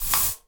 spray_bottle_13.wav